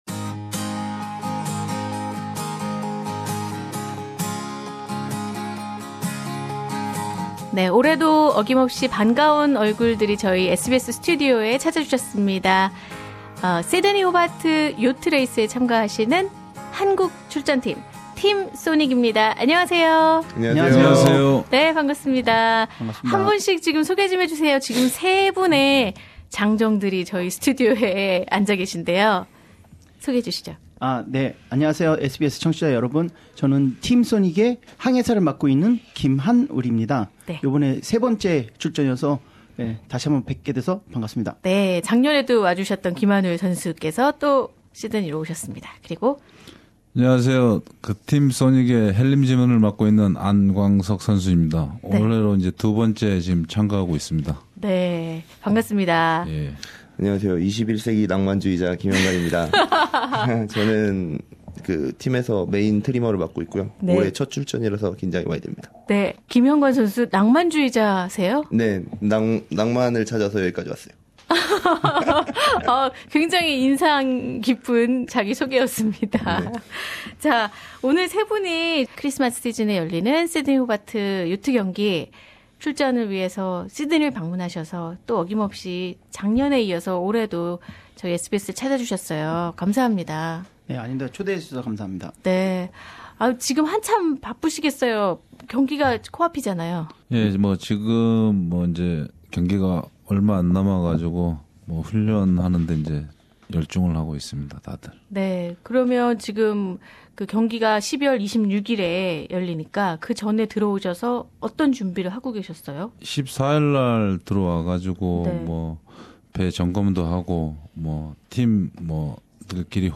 작년에 이어 두번째 참가하는 한국 참가팀 소닉이 SBS와의 인터뷰를 통해 성공적인 세일링의 각오를 다졌다.